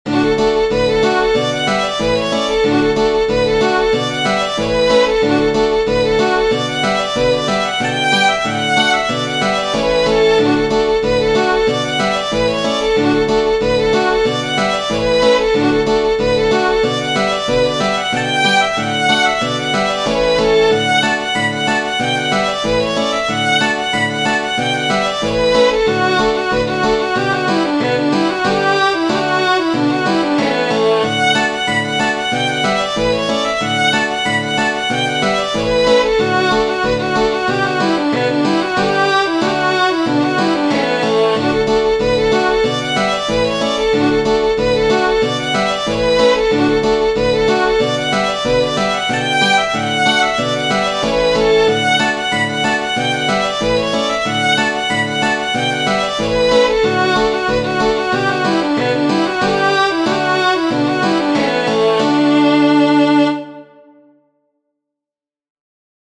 Tradizionale Genere: Folk "The Silver Spear" è una melodia irlandese molto popolare eseguita tradizionalmente con il violino, quindi è un tipico "fiddle reel".